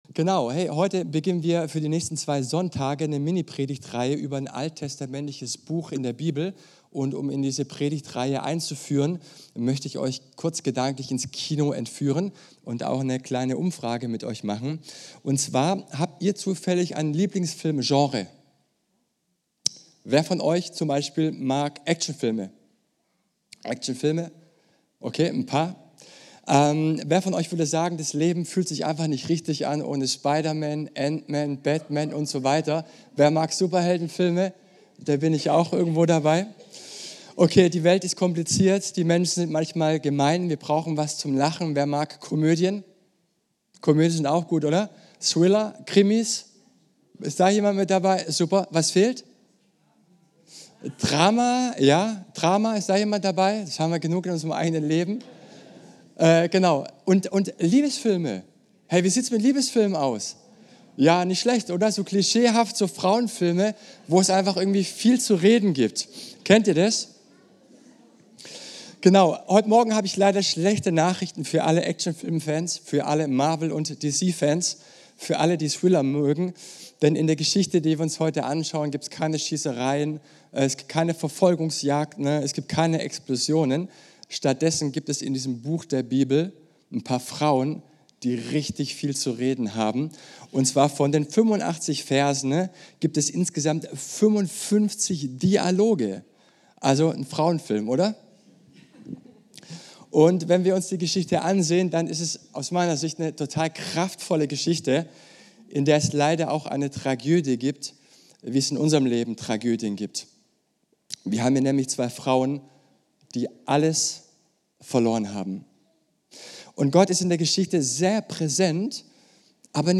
Passage: Ruth 1,1-2 Dienstart: Gottesdienst